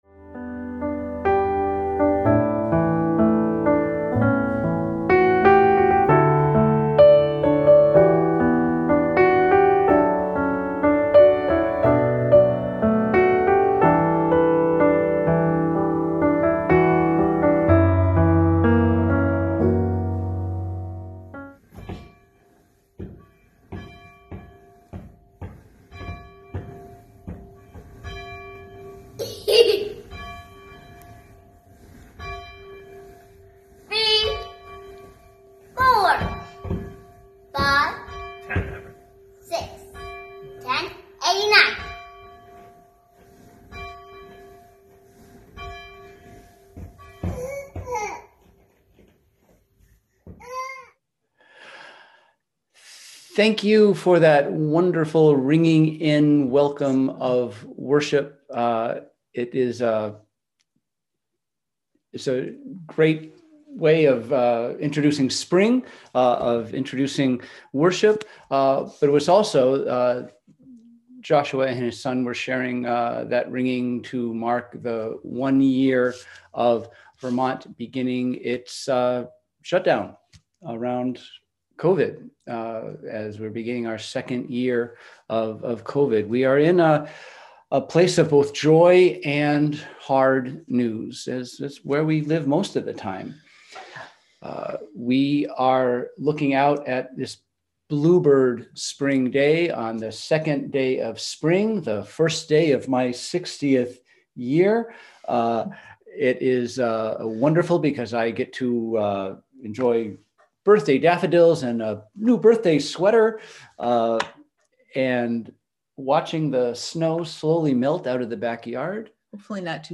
We held virtual worship on Sunday, March 21, 2021 at 10:00AM!